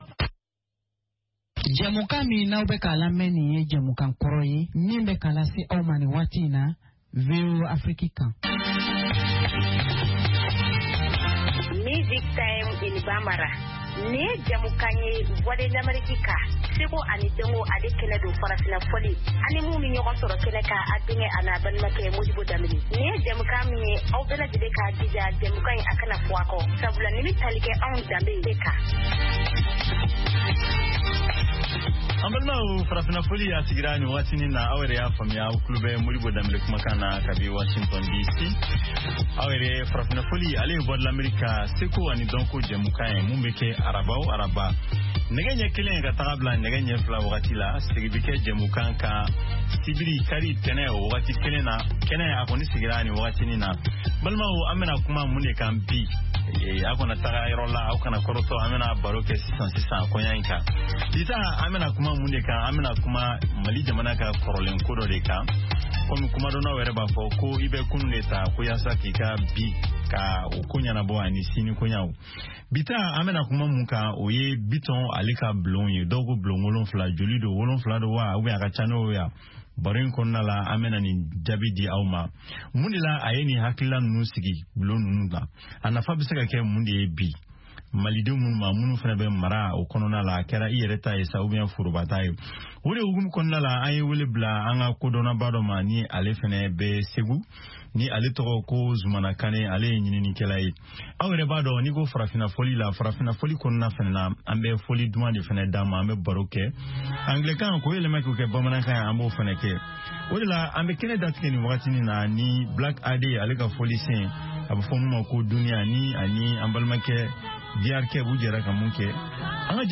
Bulletin d’information de 17 heures
Bienvenu dans ce bulletin d’information de VOA Afrique.